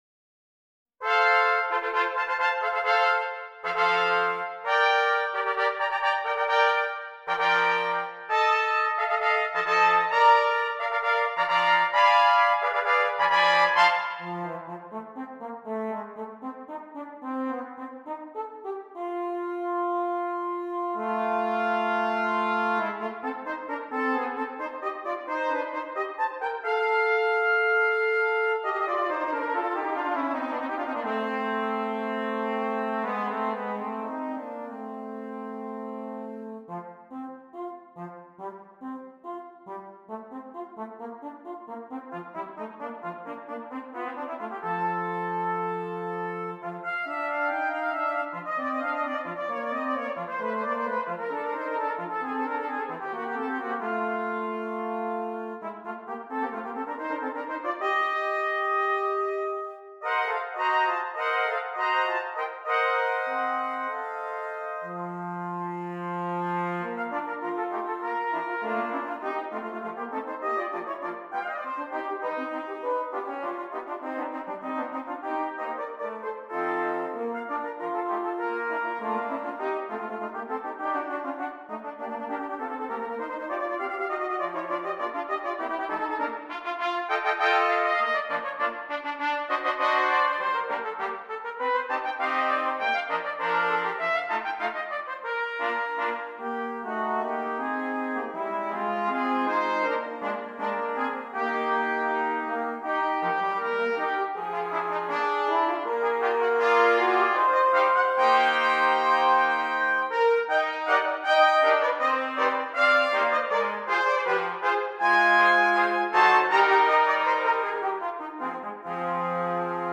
Cornet, Trombone and Piano
a cornet and trombone duet with band accompaniment